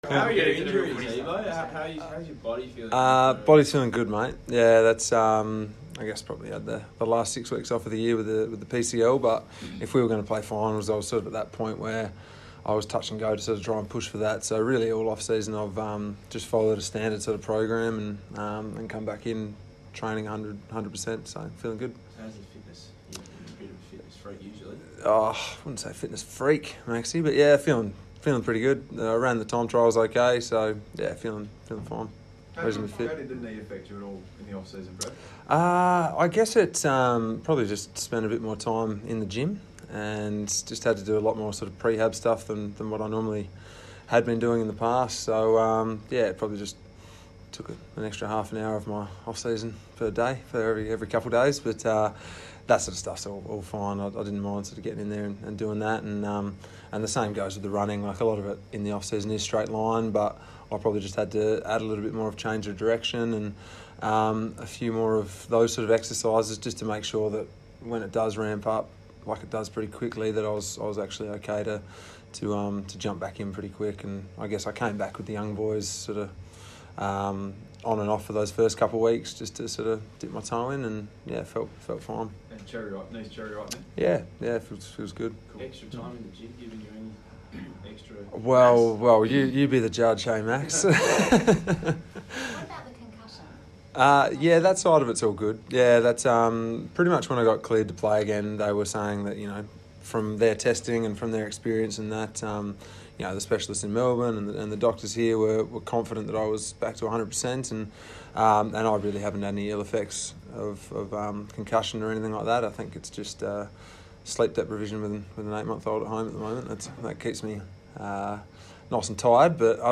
Brad Ebert press conference - Monday 25 November